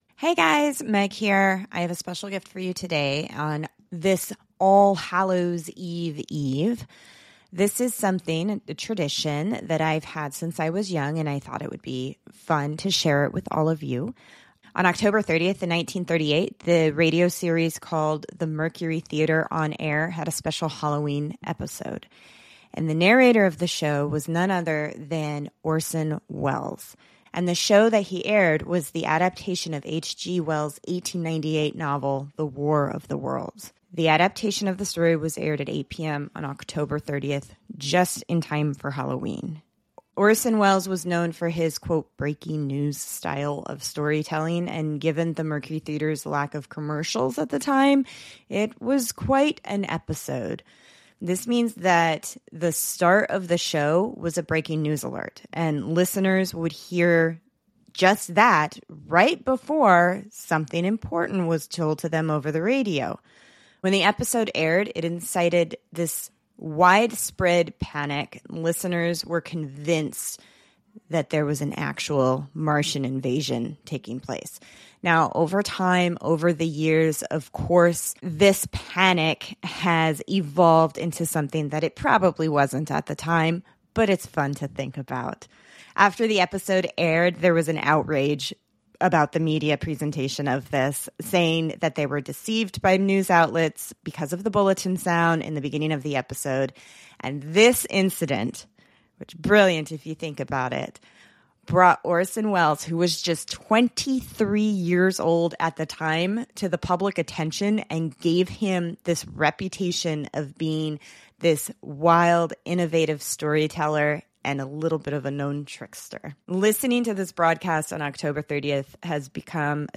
Since this night, it has become a tradition to listen to the original broadcast.